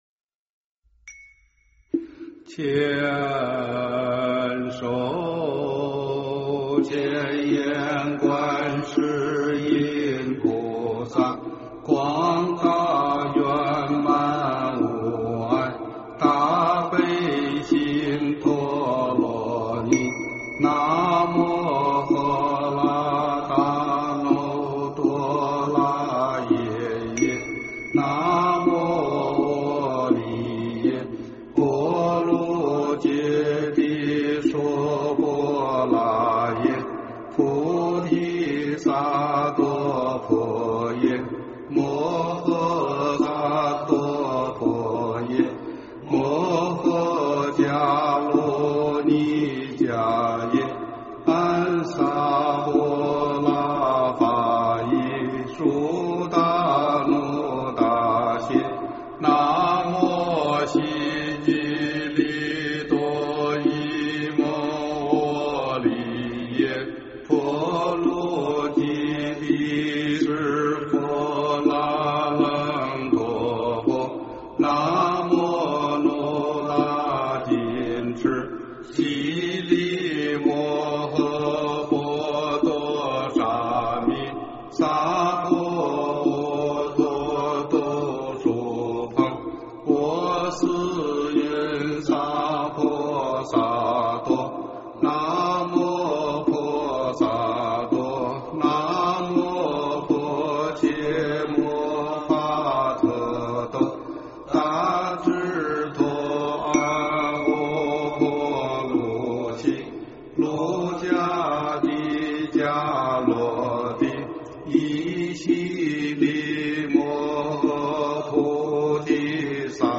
大悲咒(率众
经忏
佛音
佛教音乐